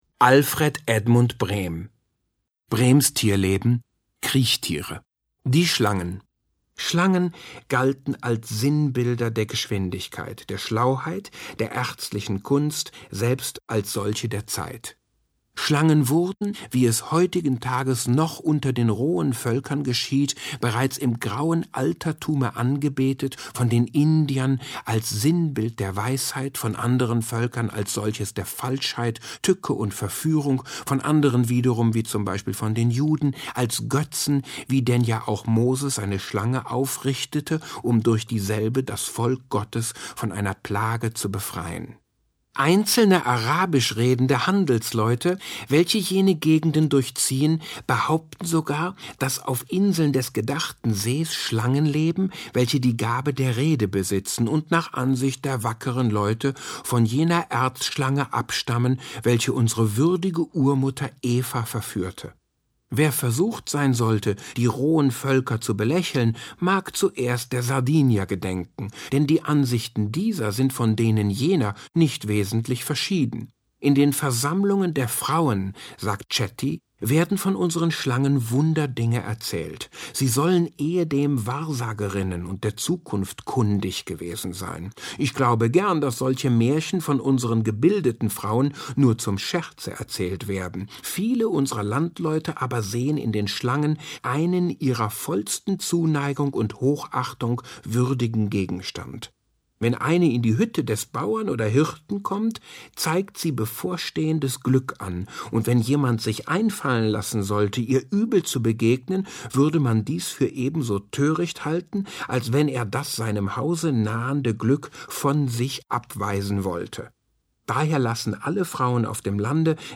Roger Willemsen (Sprecher)